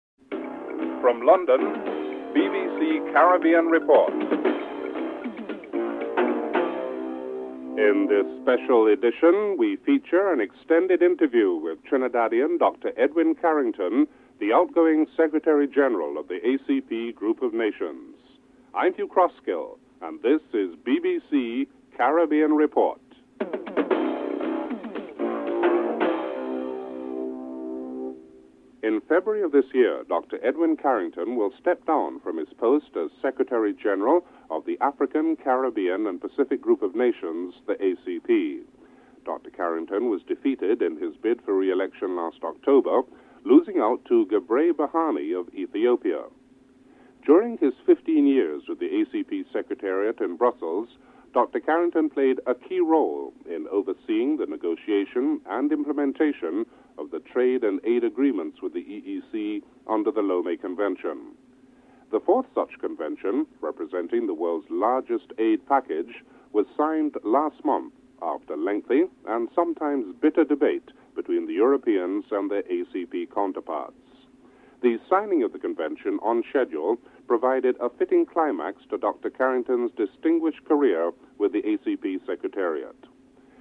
This special edition program provides an extended interview with Dr. Edwin Carrington, outgoing Secretary General of the African, Caribbean and Pacific Group of Nations.
1. Headlines (00:00-00:29)